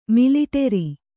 ♪ miliṭeri